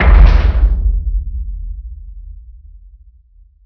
sound / doors / ir_stop.wav